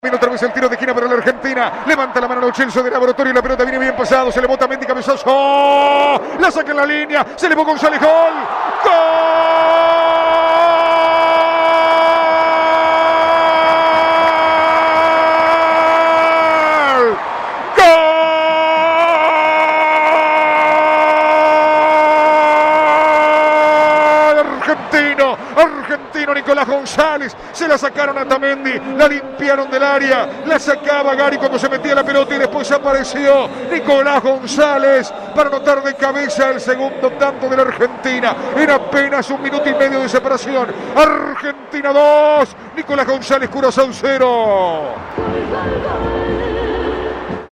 relato